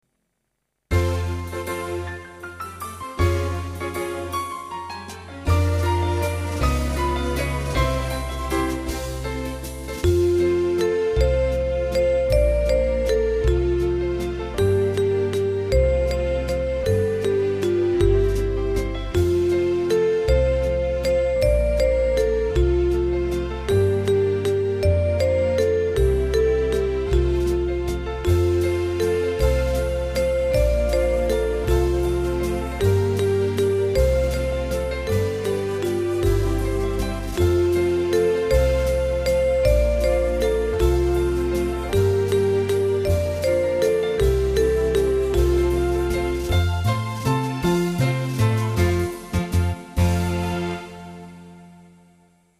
Basi musicali originali